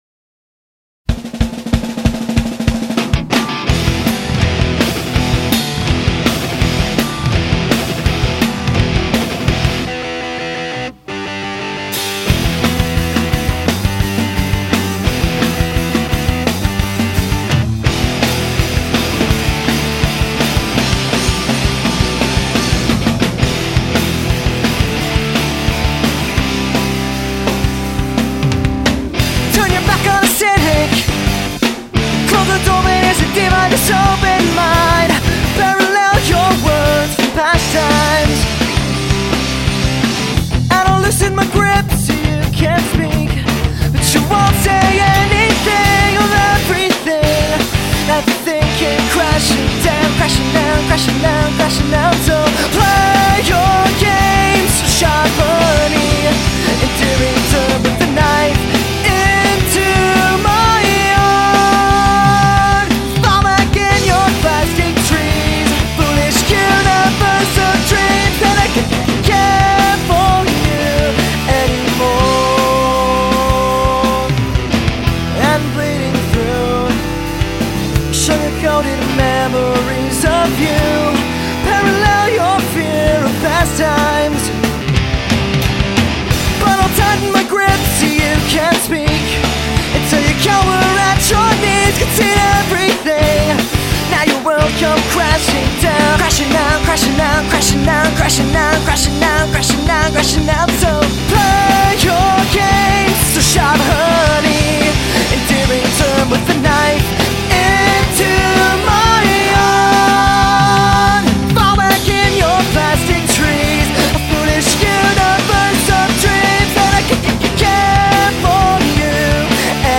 Rock/Punk/Emo from Southern California.